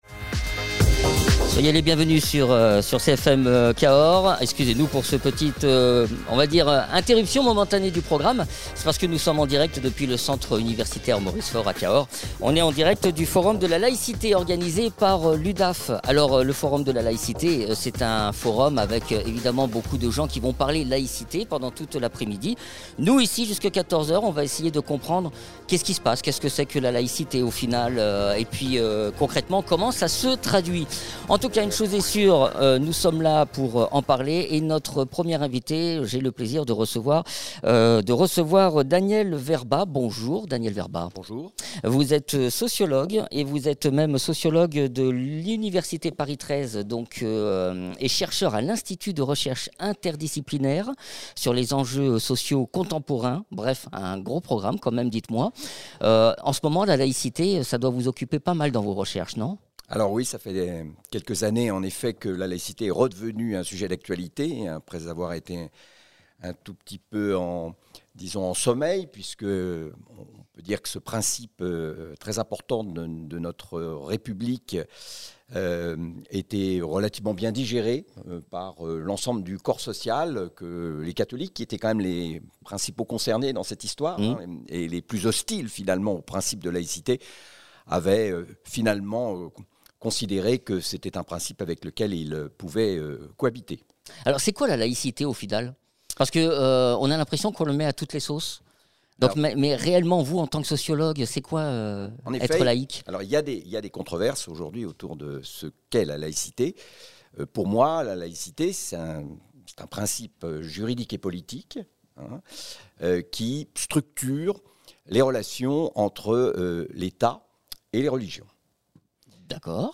L’UDAF du Lot à organisé un forum La¨cité. CFM était en émission spéciale.